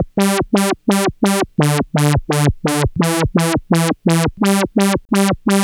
Synth 24.wav